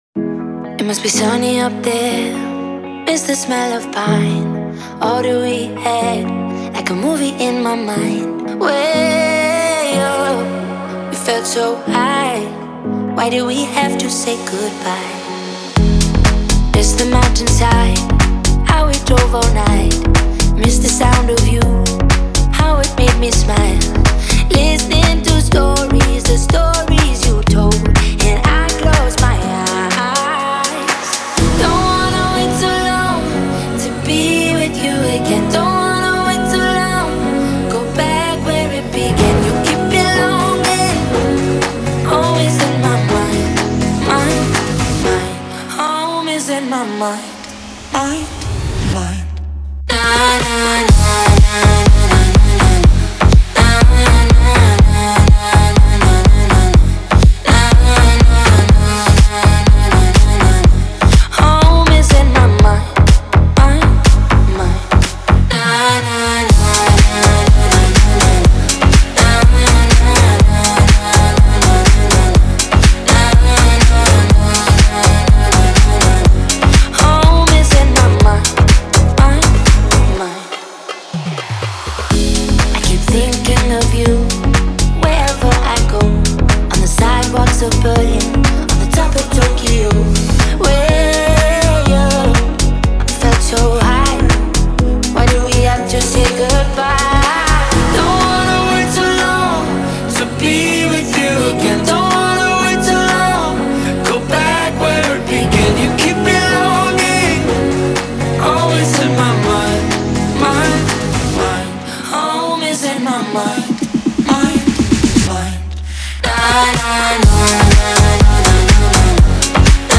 это яркая и мелодичная композиция в жанре электронного попа